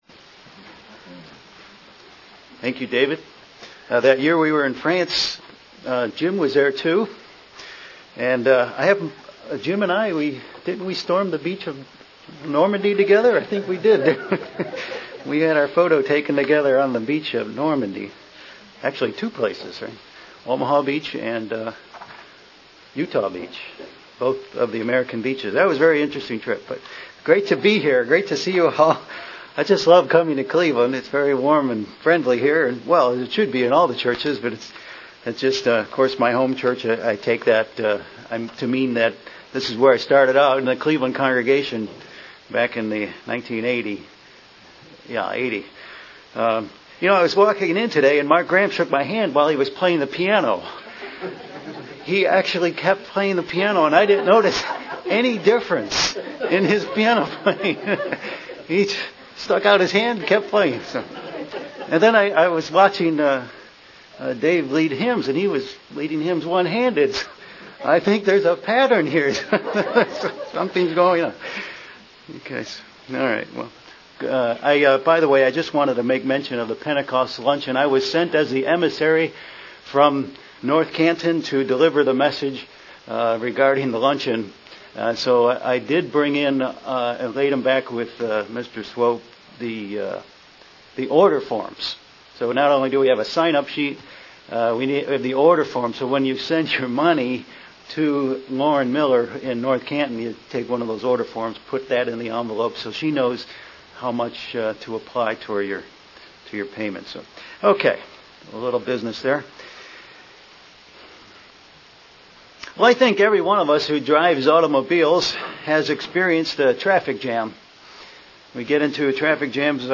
Many people limit the sacrifice of Christ to a discussion of the Passover. This Sermon will discuss why it is much more!